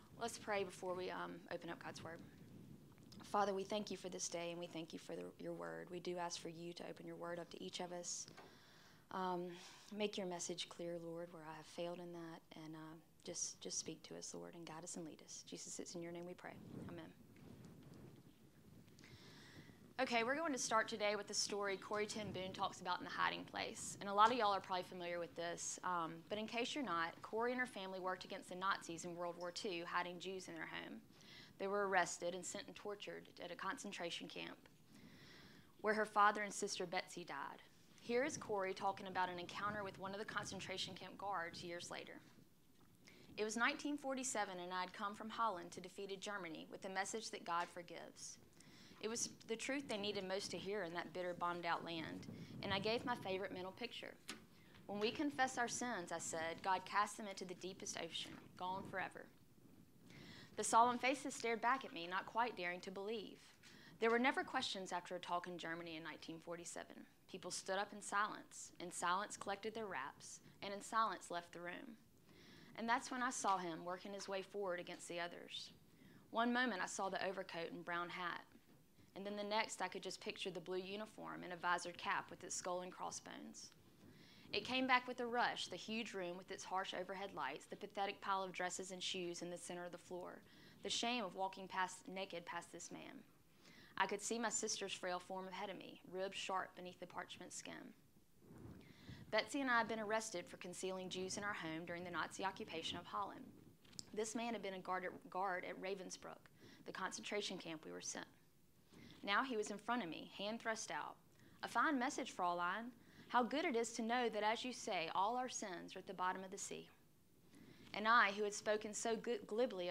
Hebrews Lesson 18